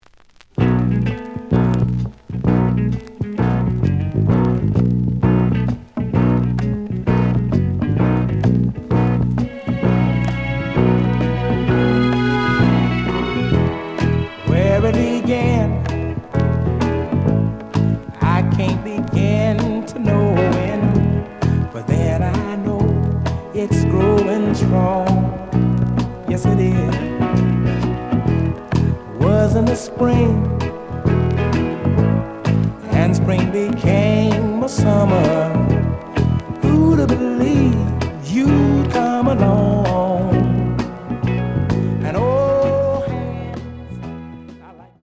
試聴は実際のレコードから録音しています。
The audio sample is recorded from the actual item.
●Genre: Soul, 70's Soul